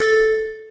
Techmino/media/effect/chiptune/warn_1.ogg at 89134d4f076855d852182c1bc1f6da5e53f075a4
warn_1.ogg